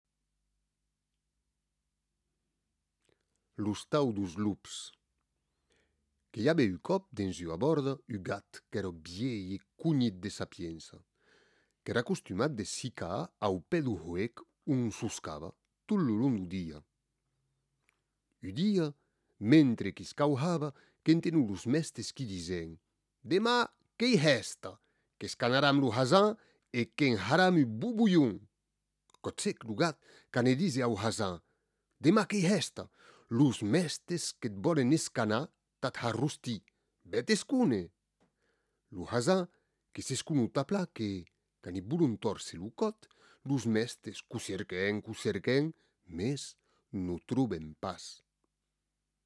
Version en occitan gascon